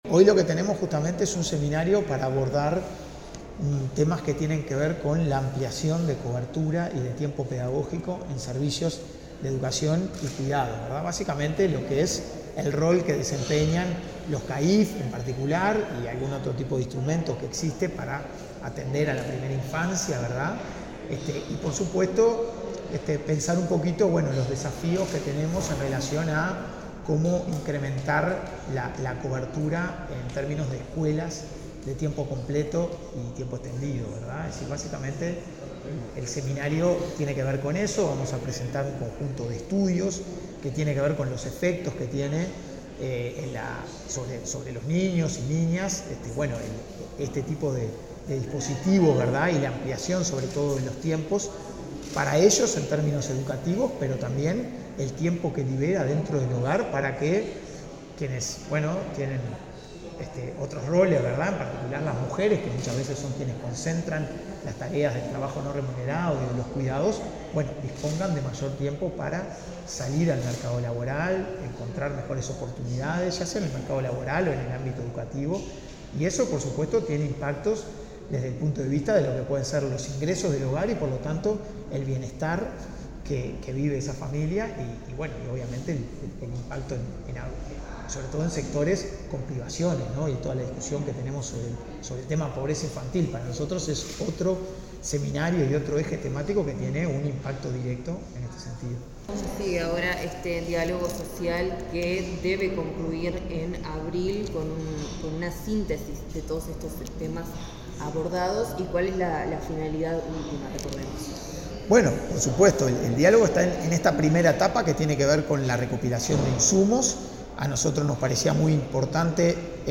Declaraciones del coordinador del Diálogo Social, Hugo Bai
Declaraciones del coordinador del Diálogo Social, Hugo Bai 09/10/2025 Compartir Facebook X Copiar enlace WhatsApp LinkedIn El coordinador del Diálogo Social, Hugo Bai, realizó declaraciones en el marco del seminario evidencia para Repensar la Ampliación de cobertura y Tiempos de Educación y Cuidados.